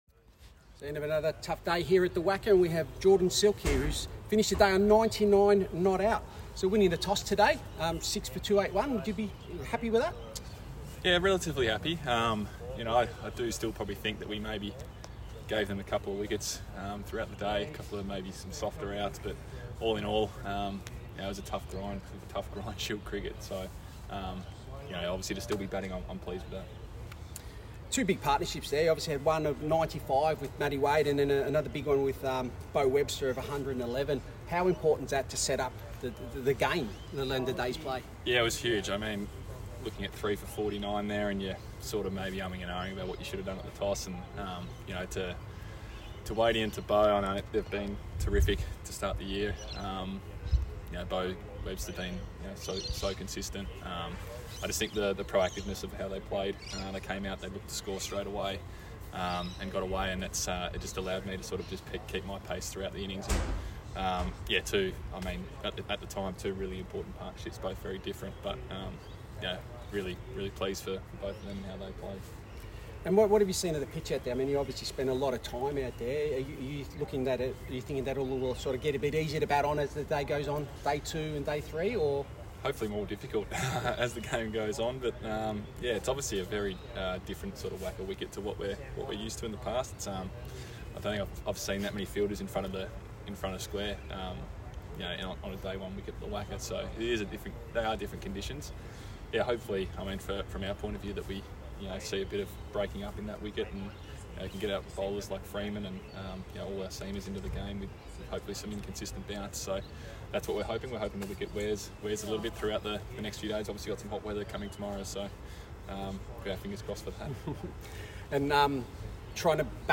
Tasmania batter Jordan Silk, post match interview, Western Australia vs Tasmania, 6th Match, Sheffield Shield